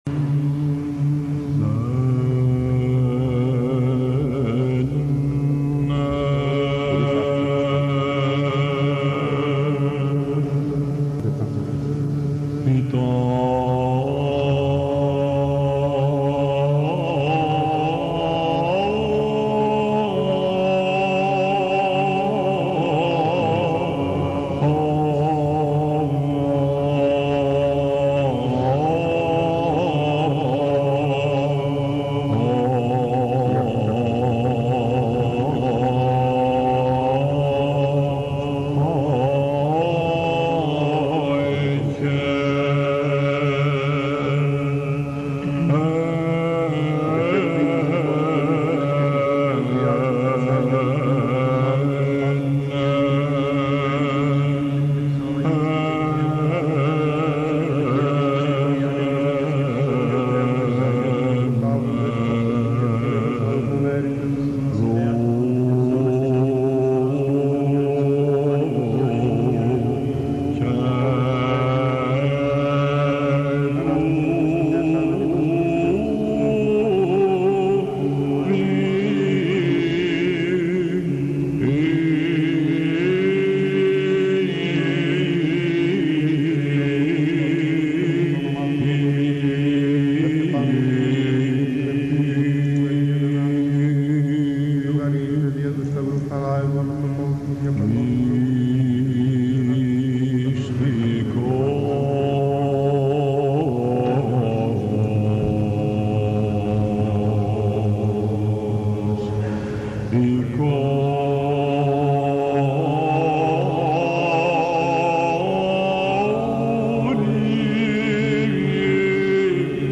ΕΚΚΛΗΣΙΑΣΤΙΚΑ
" Χερουβικό Α΄ " - Ιούνιος 1980 Αχειροποίητος Θεσ/νίκης